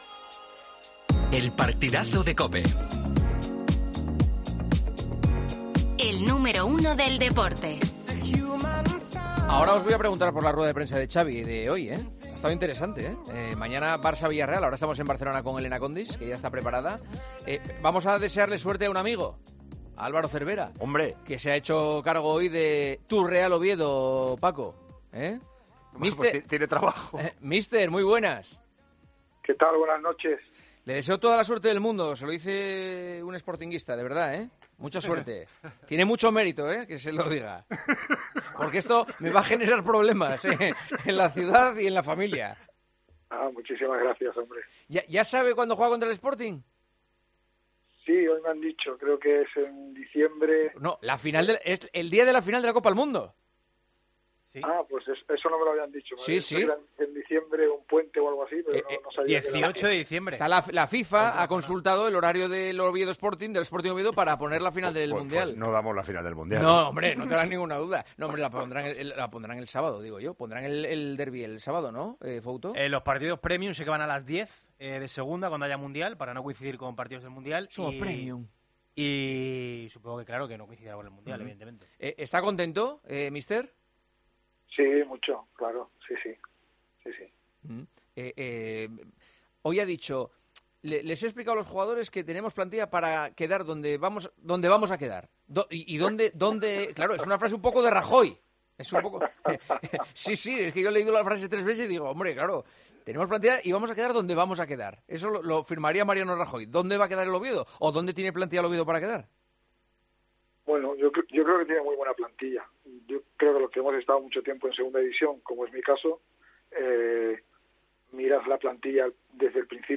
Entrevista a Álvaro Cervera en 'El Partidazo de COPE'